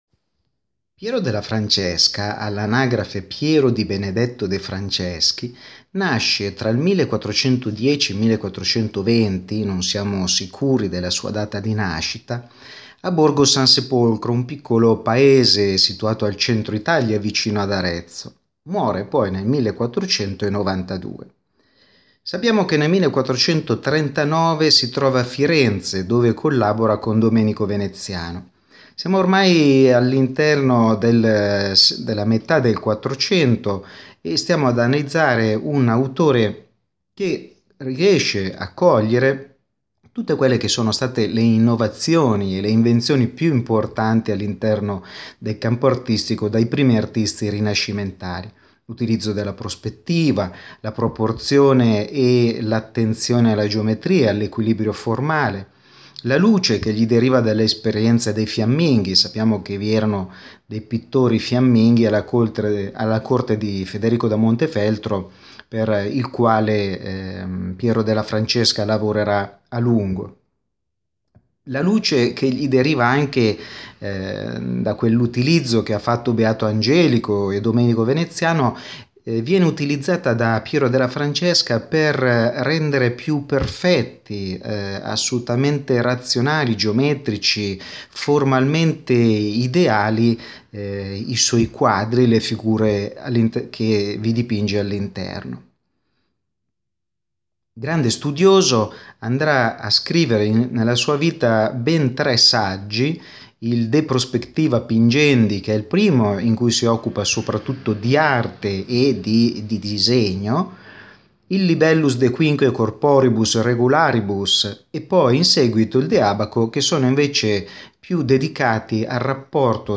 Ascolta la lezione audio dedicata a Piero della Francesca